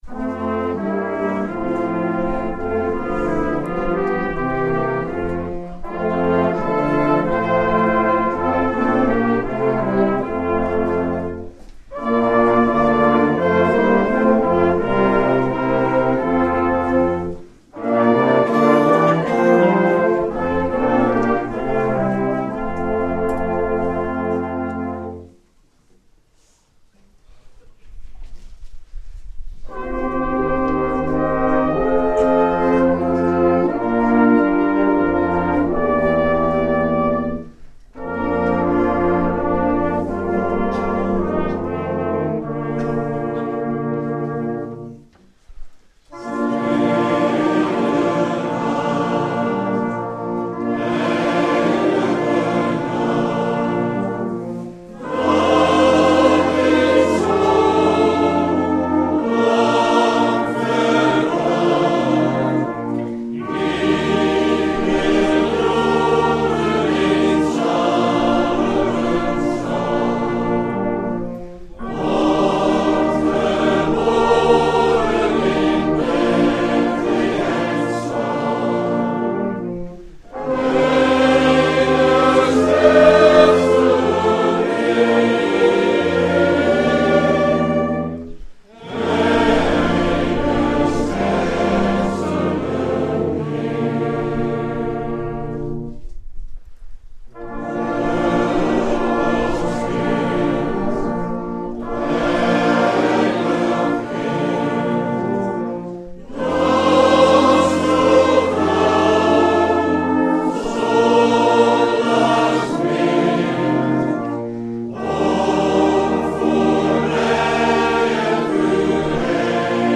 m.m.v muziekvereniging Jeduthun
Thema: Wie zoekt…die vindt! Categorie: Mattheus Label: Schriftlezing:Mathëus 2 vs 1 - 11